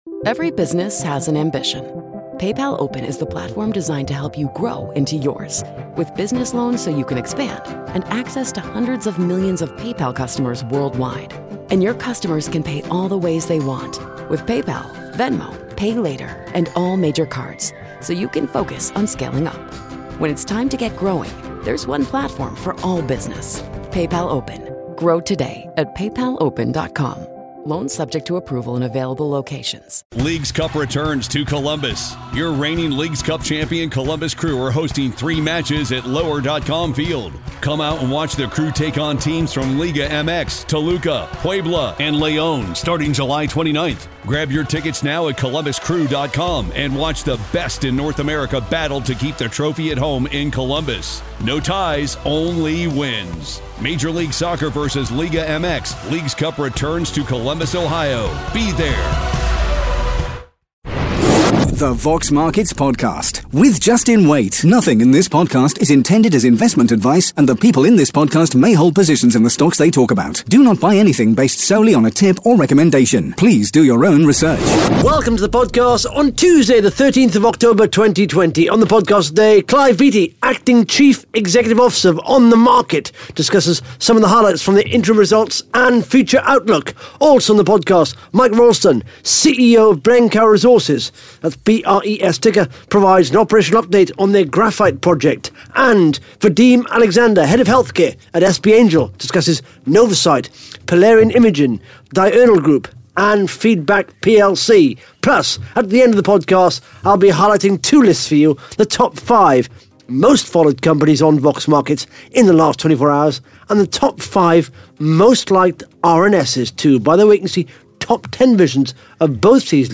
(Interview starts at 14 minutes 37 seconds)